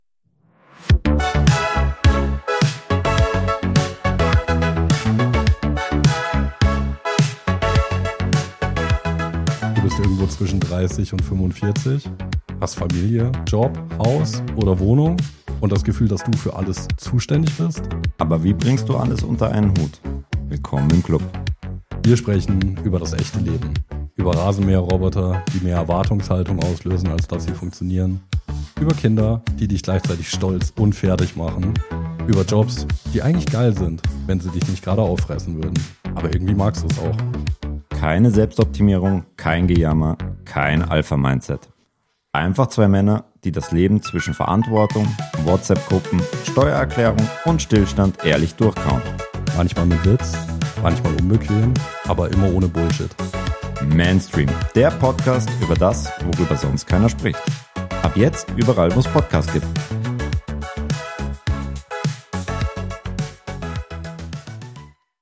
Zwei Männer reden ehrlich über das Leben, das keiner perfekt